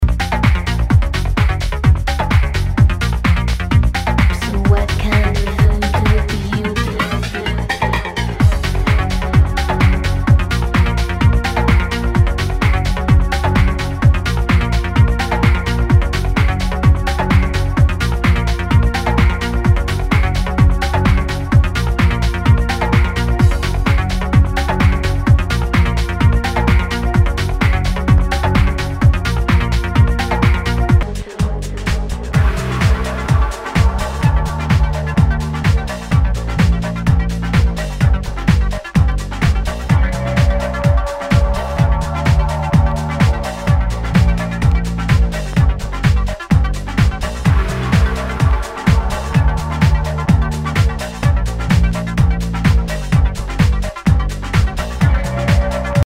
HOUSE/TECHNO/ELECTRO
ナイス！テック・ハウス！